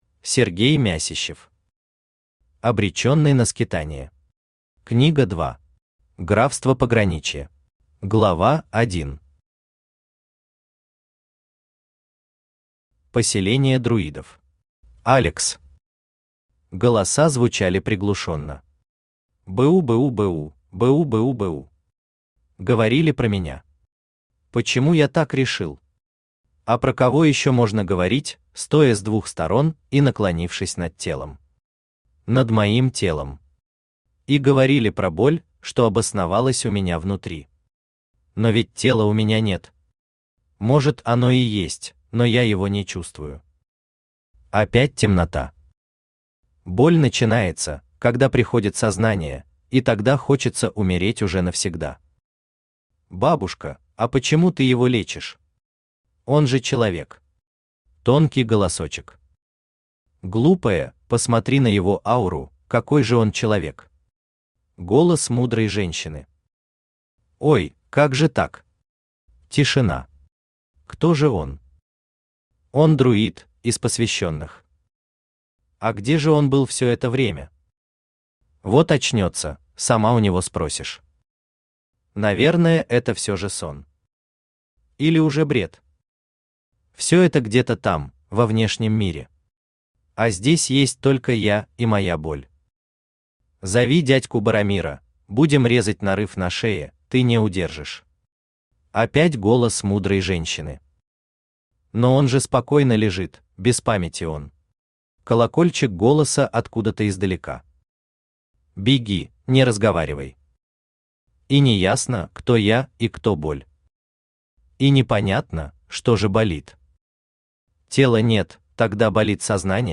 Аудиокнига Обреченный на скитания. Книга 2. Графство пограничья | Библиотека аудиокниг
Графство пограничья Автор Сергей Мясищев Читает аудиокнигу Авточтец ЛитРес.